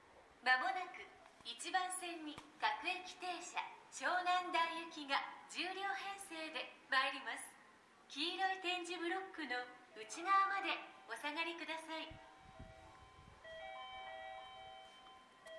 この駅では接近放送が設置されています。
接近放送各駅停車　湘南台行き接近放送です。